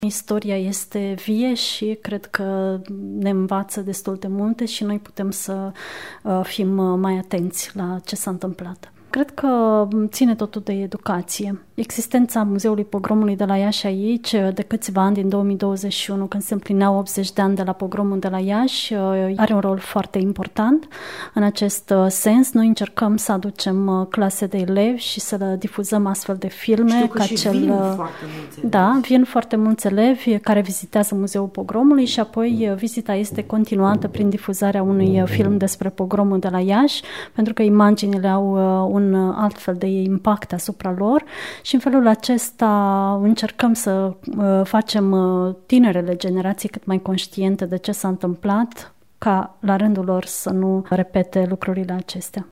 Curtea Casei Muzeelor a găzduit, miercuri, un ceremonial de comemorare a miilor de evrei uciși între 27 și 30 iunie 1941, în spațiul care atunci aparținea Chesturii Poliției și Jandarmeriei Române.